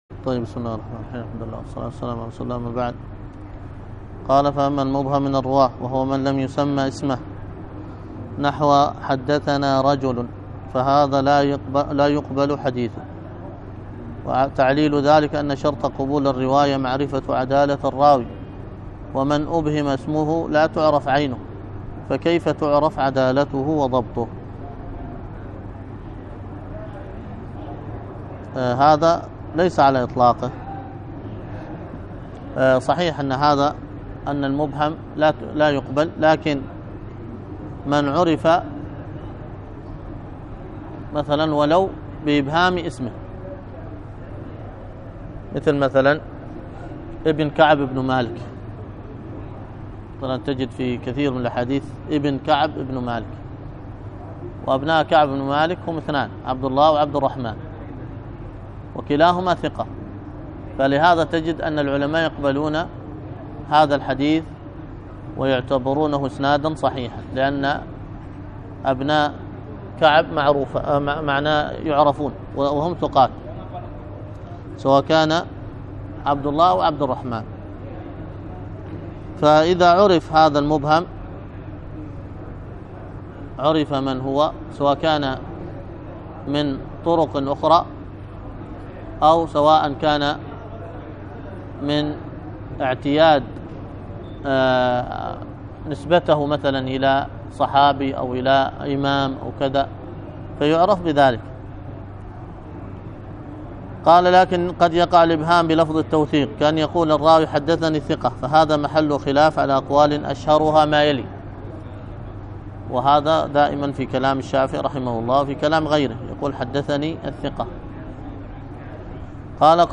الدرس